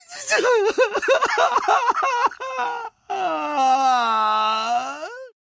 Memes